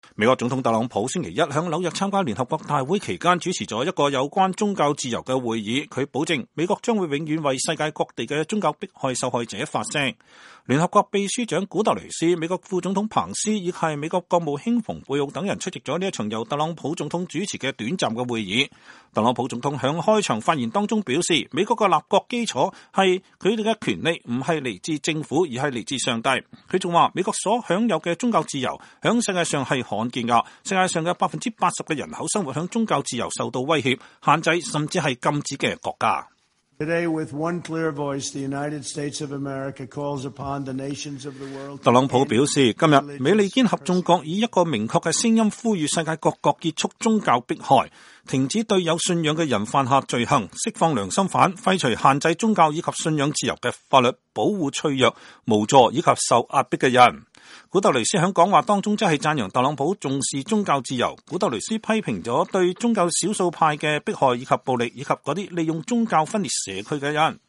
特朗普總統在開場發言中說，美國的立國基礎是“我們的權利不是來自政府，而是來自上帝”。